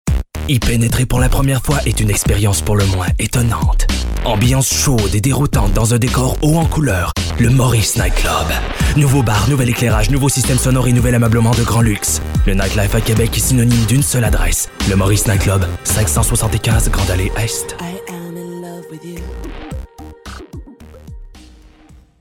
Timbre Médium - Grave
Maurice Nightclub - Sensuel - Débit rapide - Français soutenu /